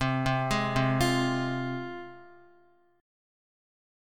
Csus4#5 chord